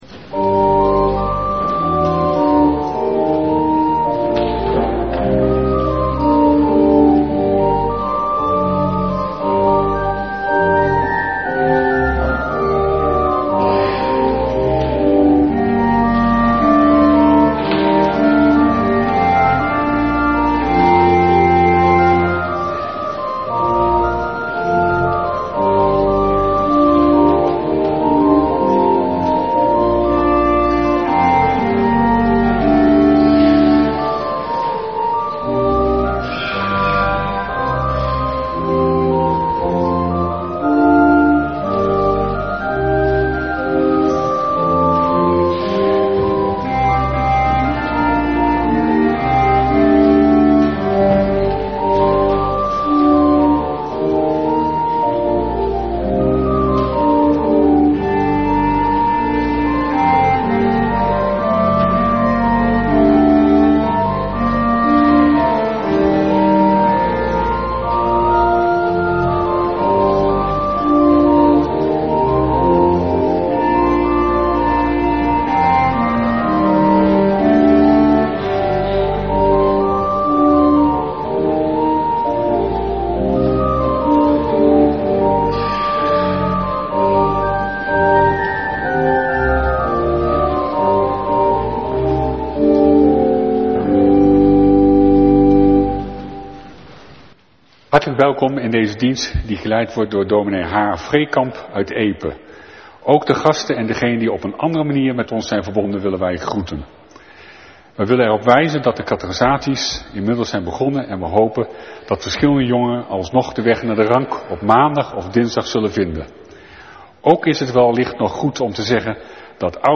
Kerkdienst beluisteren Orde van dienst